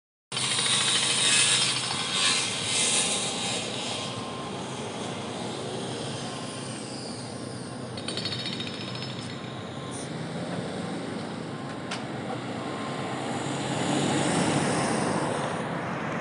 Així sona sobre el terreny el major projecte de renovació urbanística de Dinamarca.
The_sound_of_Gellerup__upload.mp3